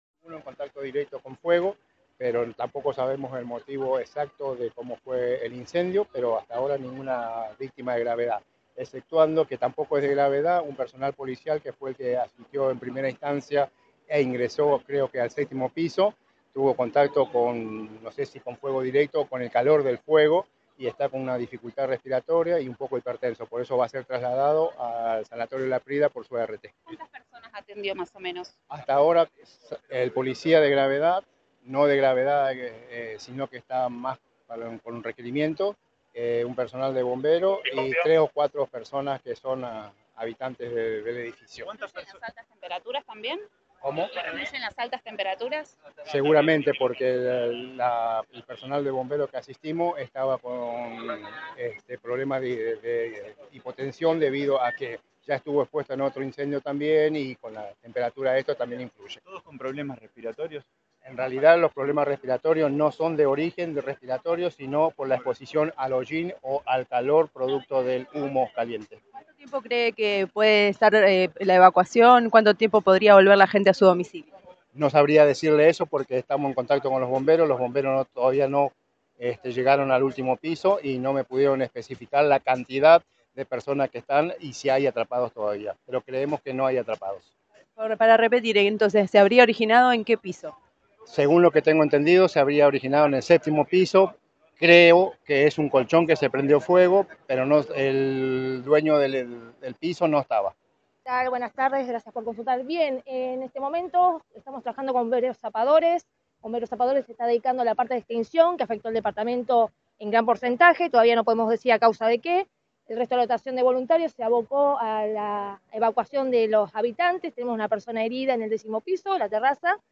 El hecho tuvo lugar en Grandoli y Sánchez de Thompson, y según dijeron al móvil de Cadena 3 Rosario, las llamas se originaron en una dormitorio.